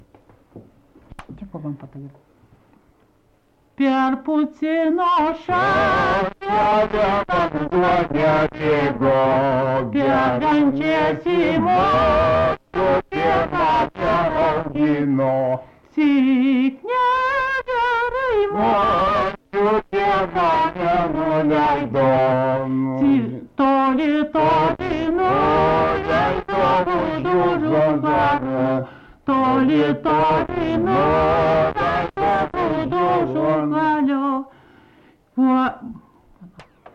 Subject daina
Erdvinė aprėptis Raitininkai
Atlikimo pubūdis vokalinis